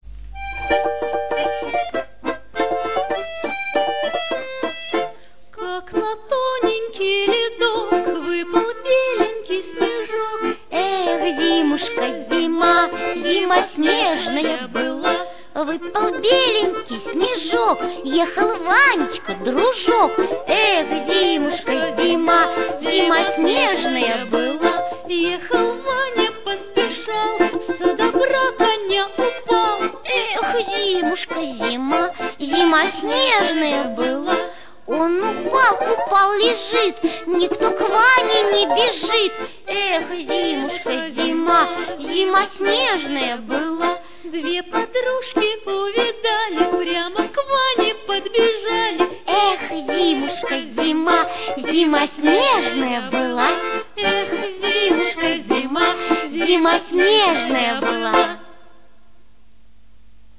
русская народная песня